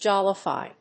音節jol・li・fy 発音記号・読み方
/dʒάləfὰɪ(米国英語), dʒˈɔləfὰɪ(英国英語)/